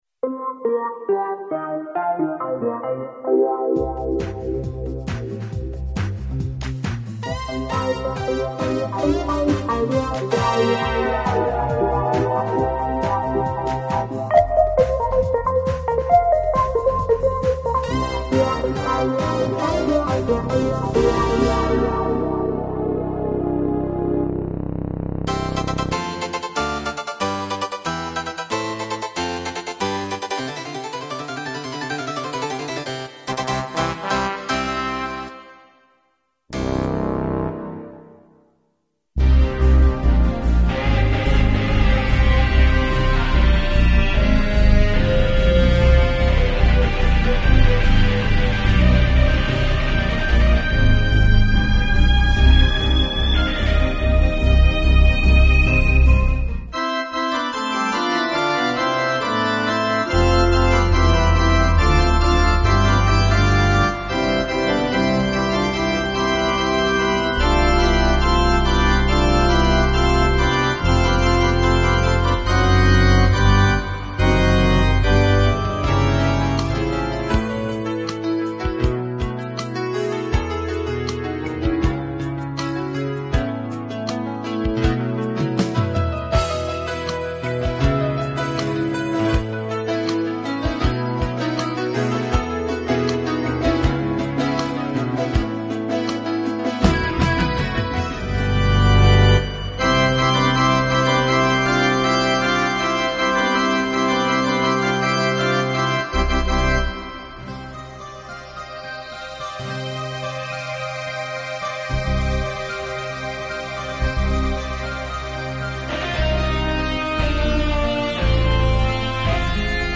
Orchestral & Instrumental Composer